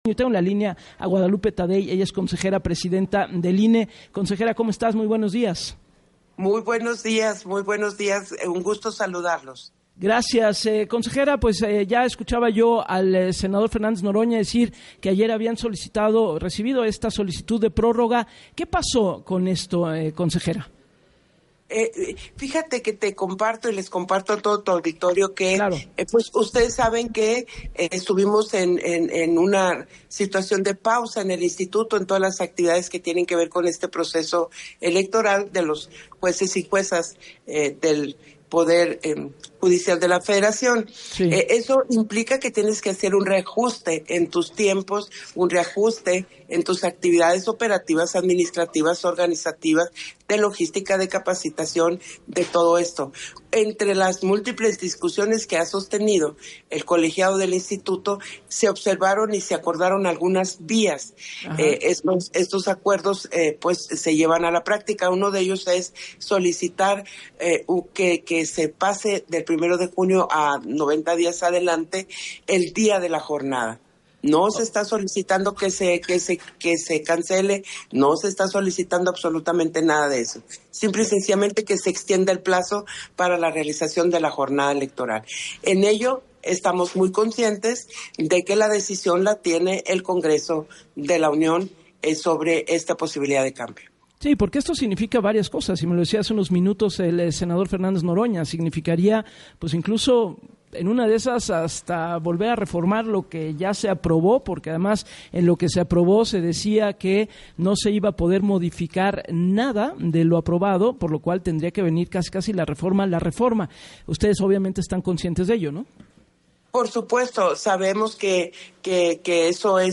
No obstante, afirmó en el espacio de “Así las Cosas” con Gabriela Warkentin, que las labores en torno a la elección del Poder Judicial continúan, pero “el proceso estaría con mayor tranquilidad y con tiempo operativos, pero si no, ajustamos los tiempos”.